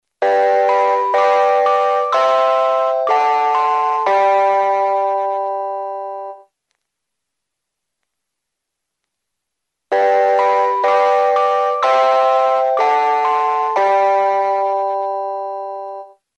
192 Кб 04.10.2010 23:06 Слегка обработано.